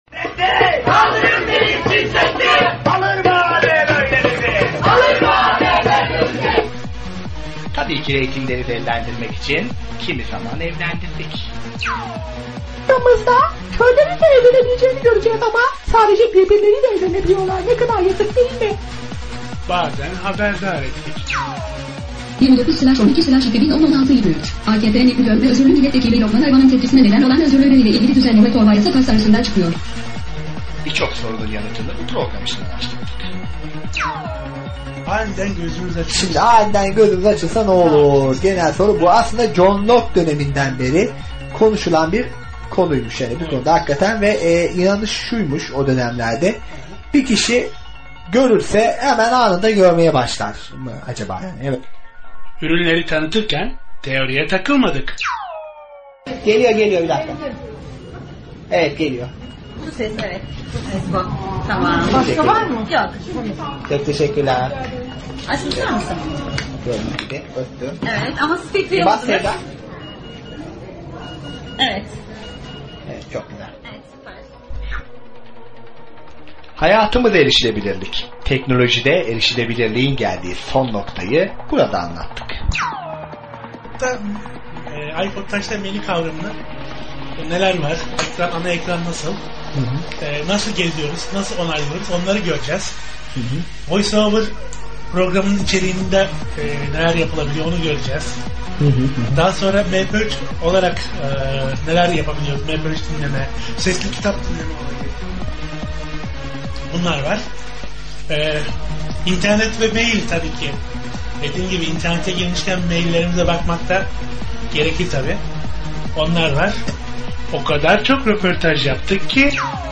Bu akşam sizlere bir kafeden sesleneceğiz.